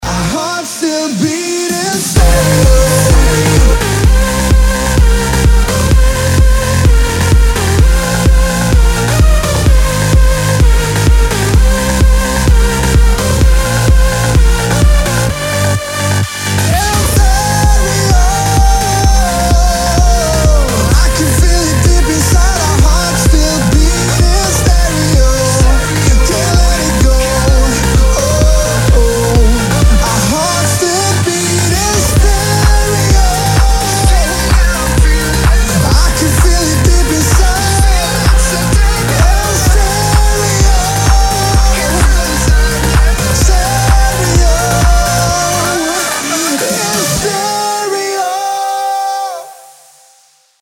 Новый Электро-Хаус Релиз от коллектива